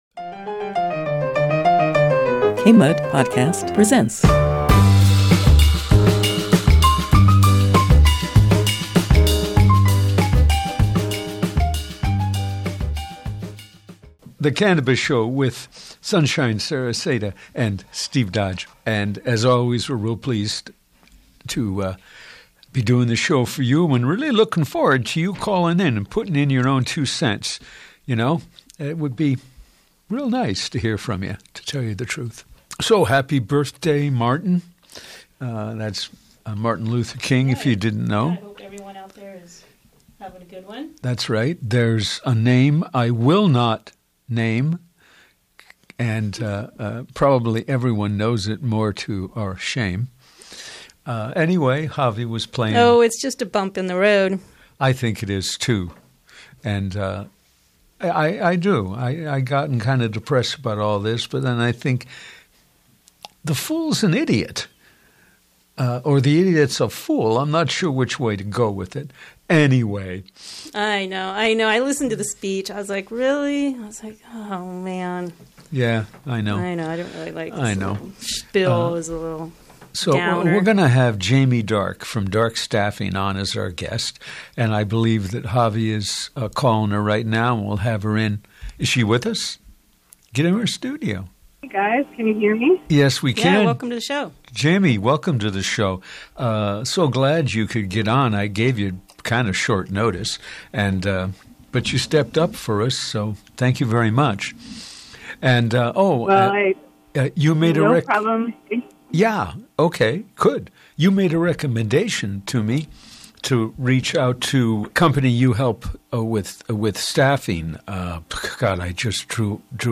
In this episode we interview